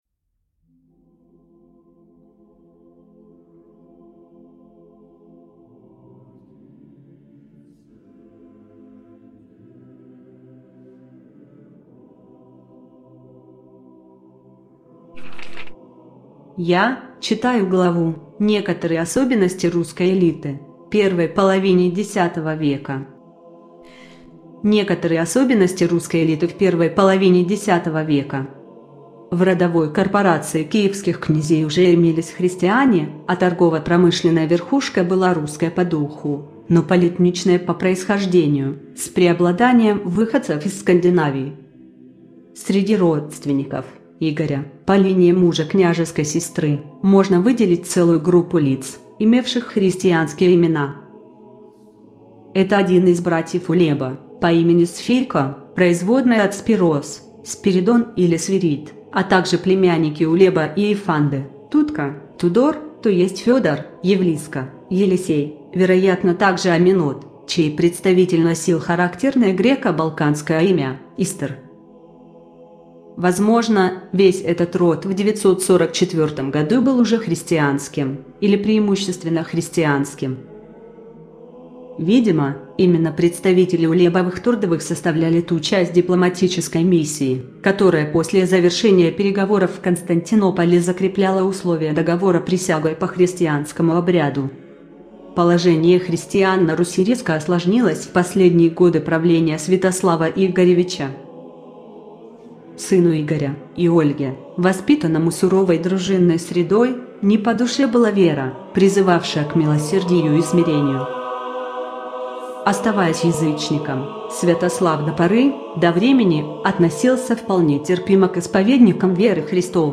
Аудиокнига: Иоакимовская летопись